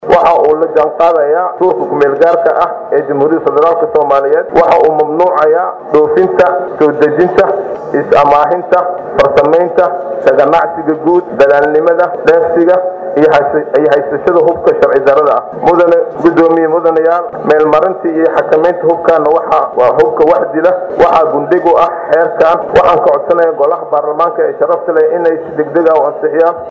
Wasiirka Wasaaradda Amniga ee Dowladda Federaalka Soomaaliya Maxamed Abuukar Islow (Ducaale) ayaa maanta Xildhibaanada ka hor aqriyay hindise sharciyeedka la dagaalanka Argagixisada iyo hindise sharciyeedka xakameynta hubka wax dila.